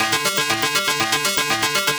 Index of /musicradar/8-bit-bonanza-samples/FM Arp Loops
CS_FMArp C_120-A.wav